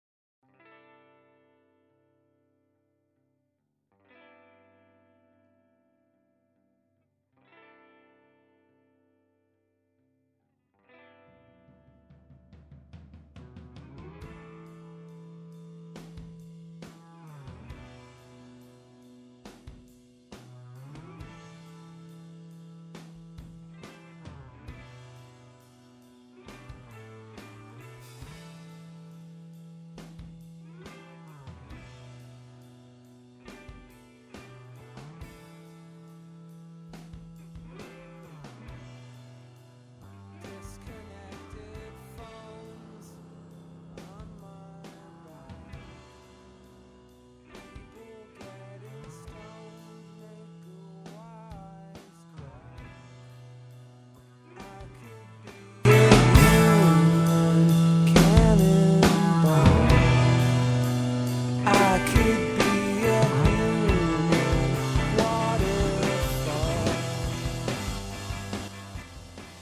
Recorded at Pachyderm Studios, Cannon Falls, MN